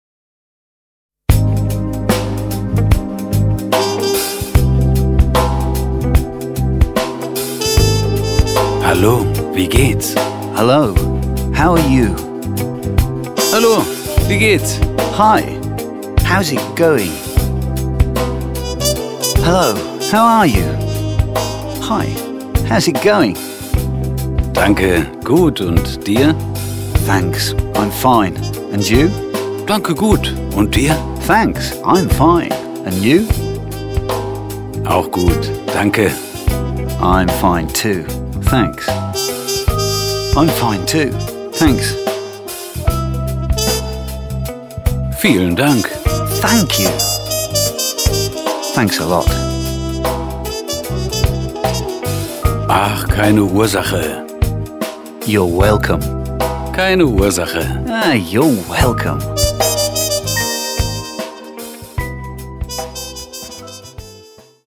Groovy Basics.Coole Pop & Jazz Grooves / Audio-CD mit Booklet
Die einzigartige Mischung aus Hörbuch, Musik und Sprachtrainer verspricht puren Lerngenuss.
Mit den supercoolen Pop & Jazz Grooves wird der reichhaltige Wortschatz "swingend" vom Gedächtnis aufgenommen.